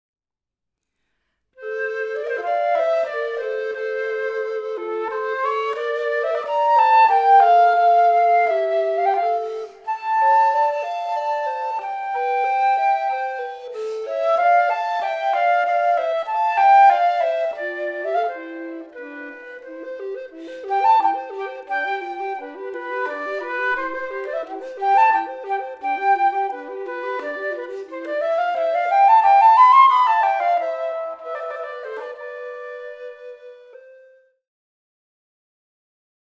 Blockflöten
Querflöten
Andante
Die Klänge der beiden Flöten können verschmelzen, sich in ihrer Unterschiedlichkeit ergänzen, sich tragen und dann wieder klingt jede Flöte so, wie sie eben auf ihre Weise klingt.